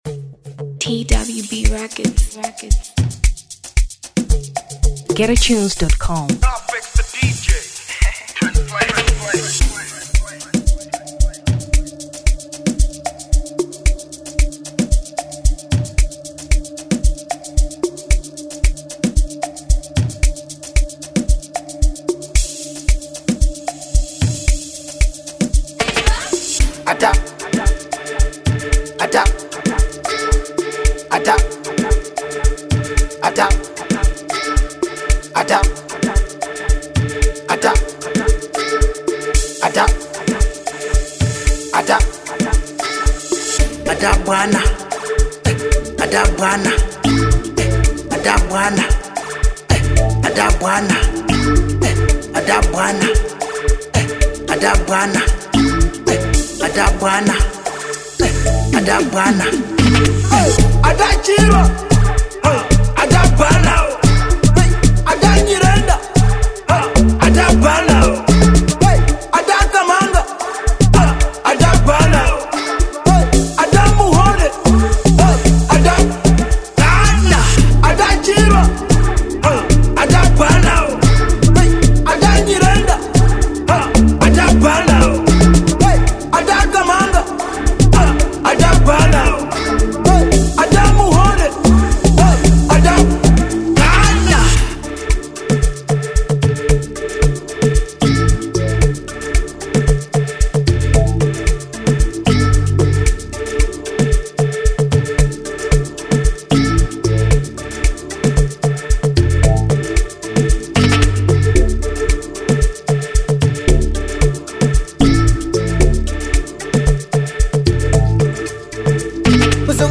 Amapiano 2023 Malawi